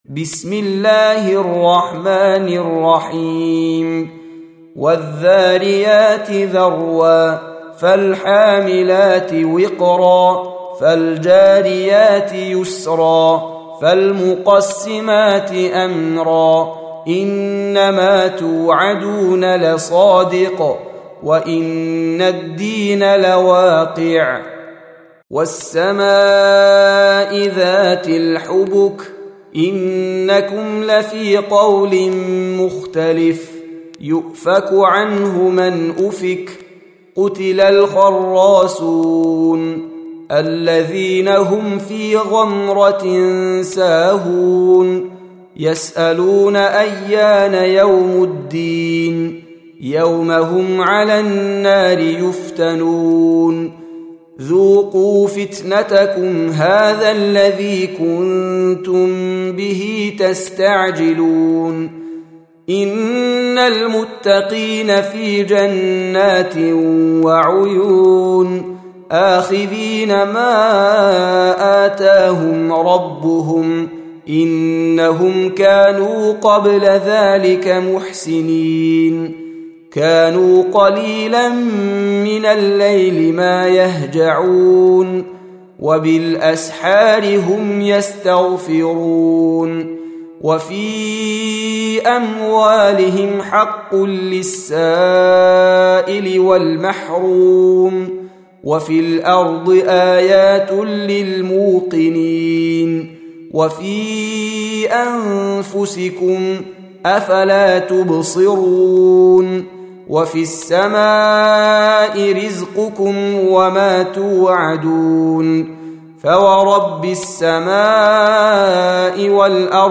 51. Surah Az-Z�riy�t سورة الذاريات Audio Quran Tarteel Recitation